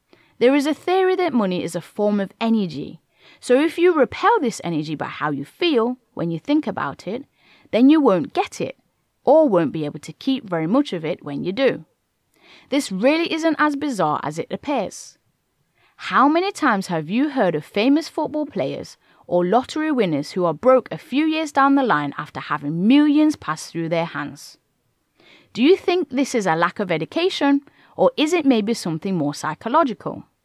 When you listen to that chapter, and compare with other chapters, is it noticeably louder?